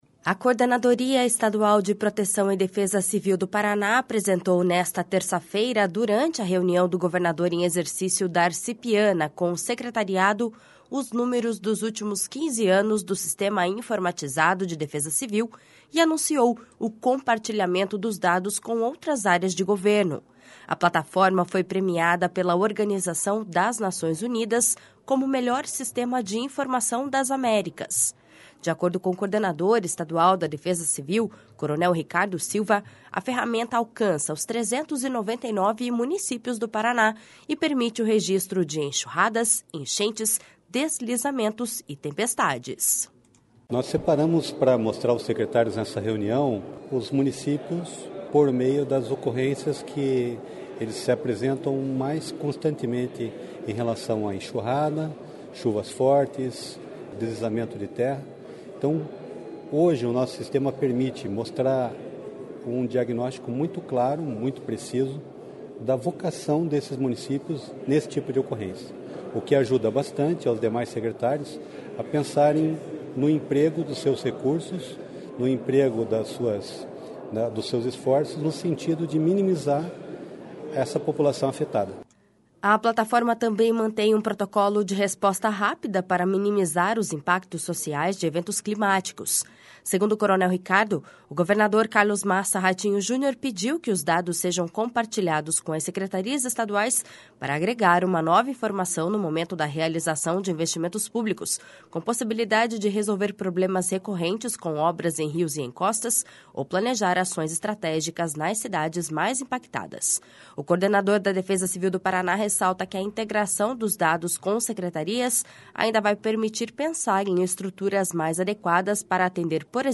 De acordo com o coordenador estadual da Defesa Civil, coronel Ricardo Silva, a ferramenta alcança os 399 municípios do Paraná e permite o registro de enxurradas, enchentes, deslizamentos e tempestades.// SONORA RICARDO SILVA.//
O governador em exercício Darci Piana marcou para o início do mês que vem uma visita técnica dos secretários estaduais à Defesa Civil para planejar as próximas ações dessa integração.// SONORA DARCI PIANA.//